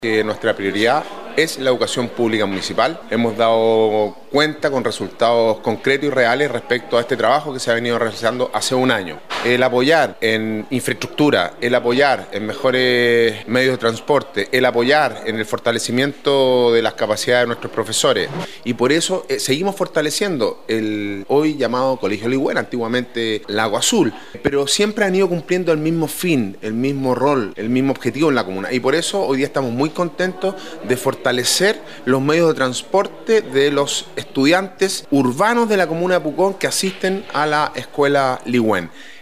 Alcalde-Sebastian-Alvarez-valora-el-subsidio-entregado-.mp3